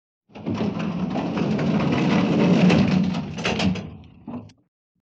効果音 転がるMP3